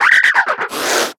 Cri de Manglouton dans Pokémon Soleil et Lune.